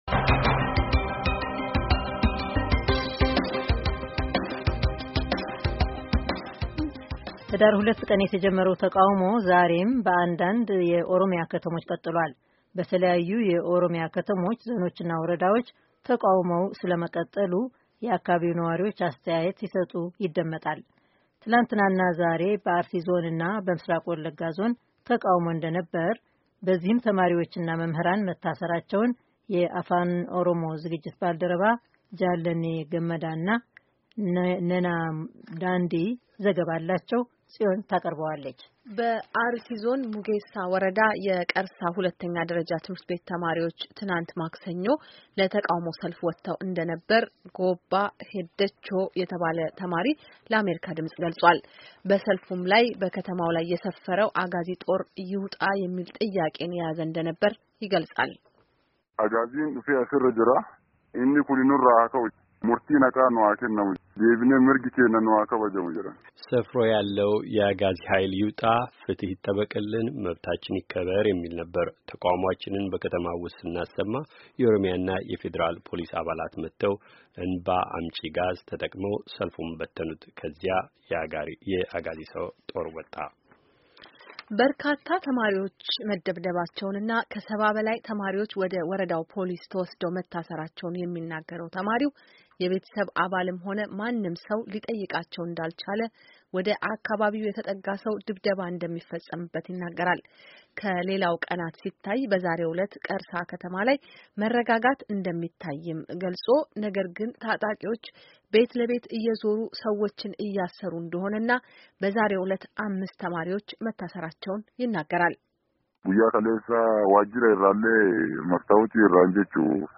በኦሮሚያ የተነሳው ተቃውሞ አሁንም በአንዳንድ አካባቢዎች መቀጠሉን የአካባቢው ነዋሪዎቸ ይናገራሉ፡፡ በአርሲና በምዕራብ ወለጋ ዞን ተማሪዎችና ነዋሪዎች እየታሠሩና ድብደባ እየተፈጸመባቸው እንደሆነ ይገልጻሉ፡፡